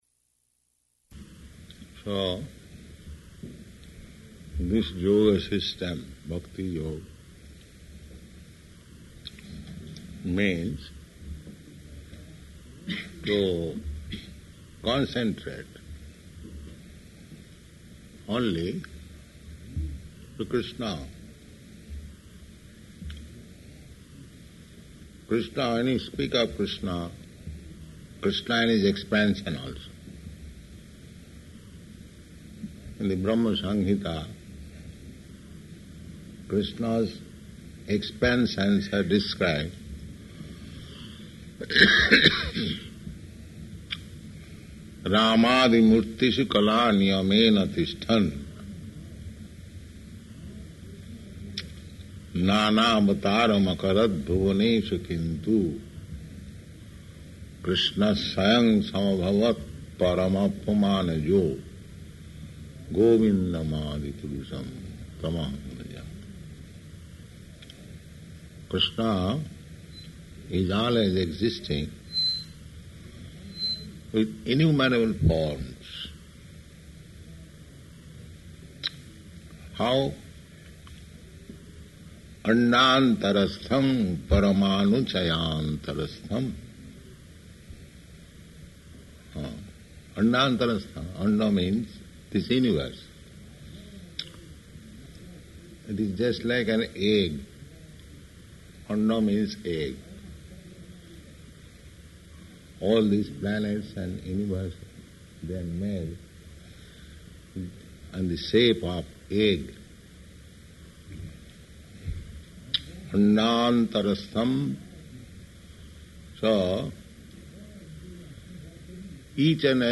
Lecture
Lecture --:-- --:-- Type: Lectures and Addresses Dated: January 15th 1969 Location: Los Angeles Audio file: 690115LE-LOS_ANGELES.mp3 Prabhupāda: So this yoga system, bhakti-yoga, means to concentrate only to Kṛṣṇa.